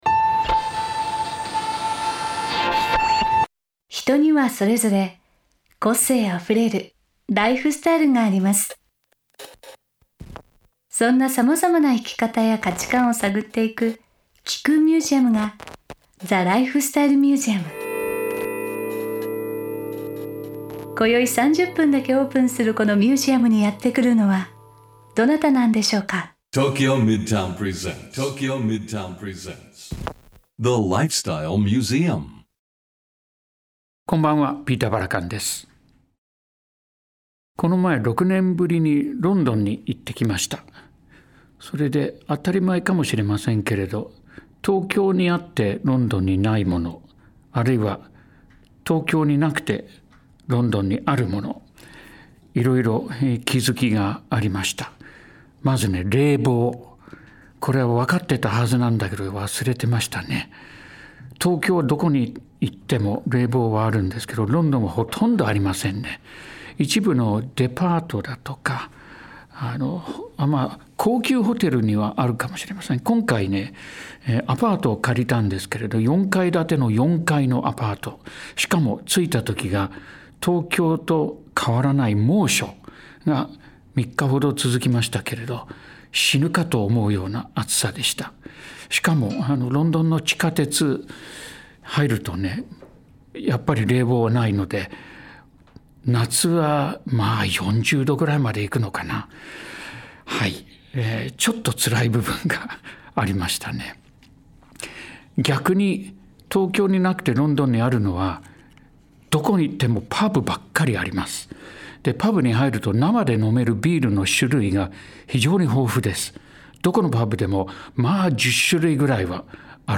ピーター・バラカン氏がメインパーソナリティーとなり、毎回様々なゲストを迎えて生き方や価値観を探っていくゲストトーク番組。